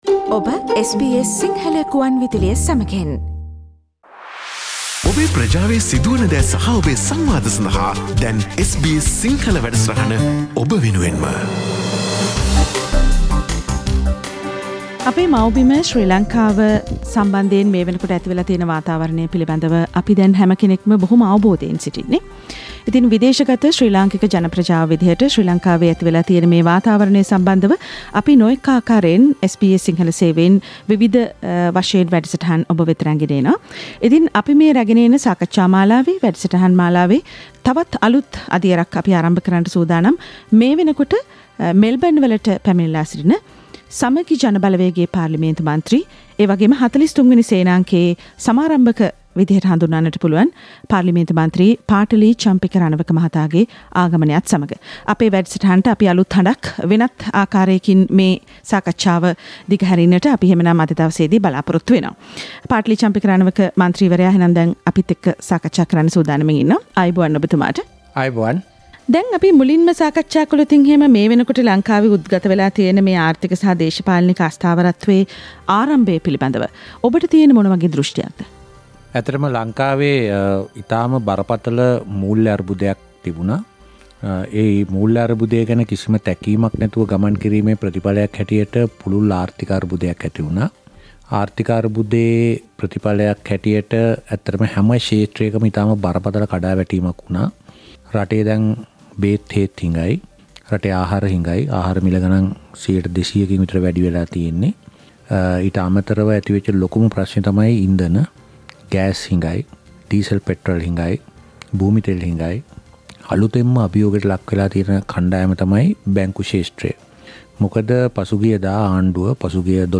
Samagi Jana Balawega MP Patali Champika Ranawaka spoke to the SBS Sinhala Radio about the recent situation in Sri Lanka during his visit to Melbourne.